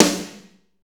Index of /90_sSampleCDs/Northstar - Drumscapes Roland/DRM_Slow Shuffle/SNR_S_S Snares x